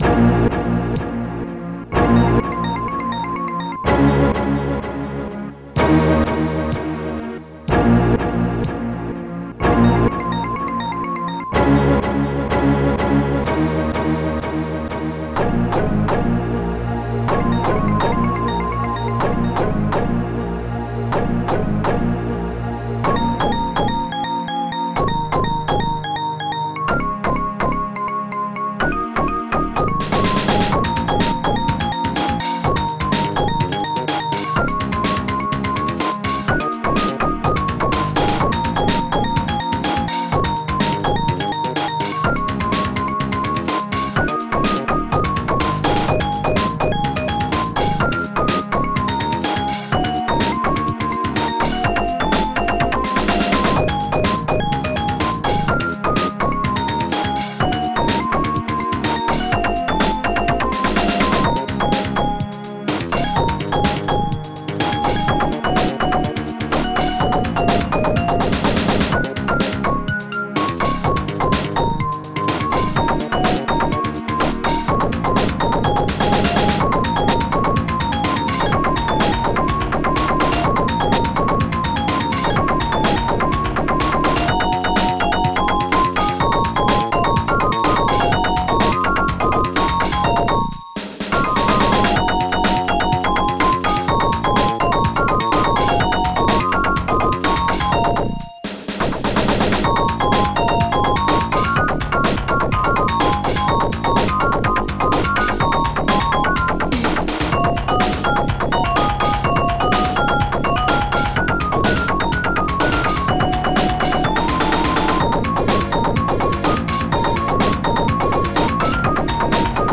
Digital Symphony Module  |  1994-05-14  |  64KB  |  2 channels  |  44,100 sample rate  |  3 minutes, 6 seconds
st-60:discostrings1
st-06:bass5
st-06:kick1
ST-02:ClosedHi5